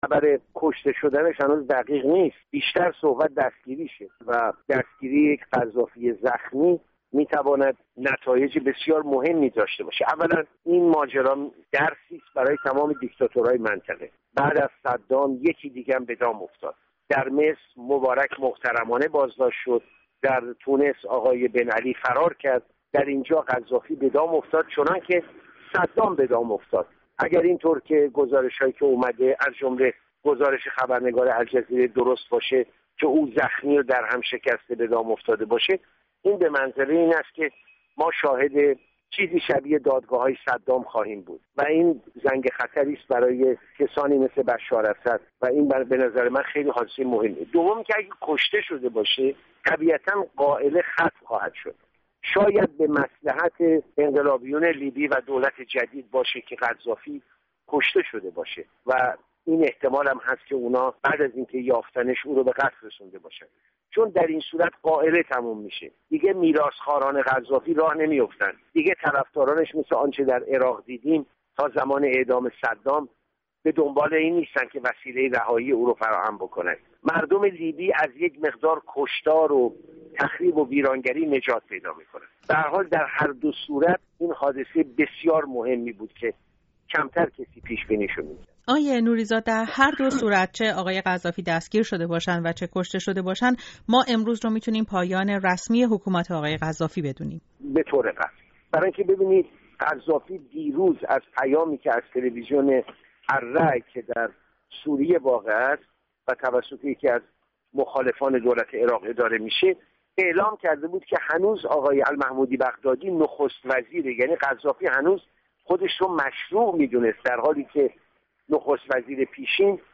تحلیلگر سیاسی